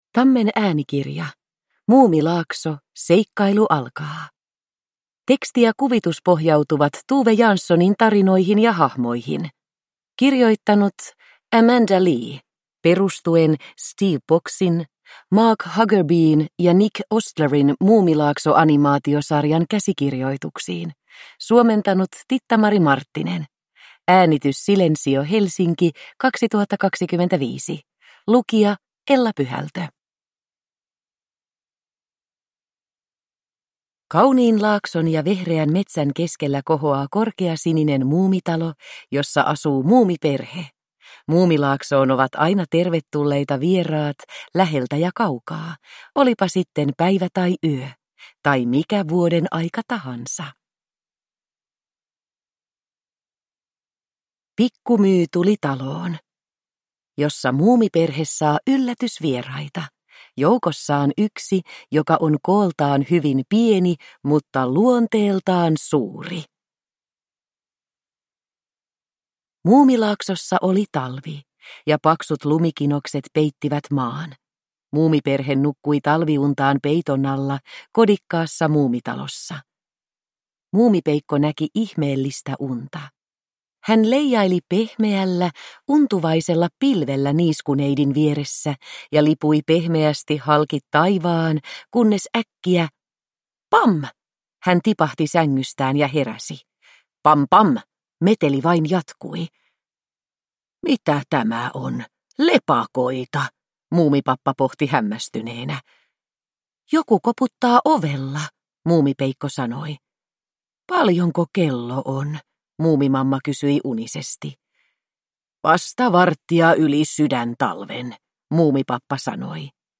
Muumilaakso. Seikkailu alkaa – Ljudbok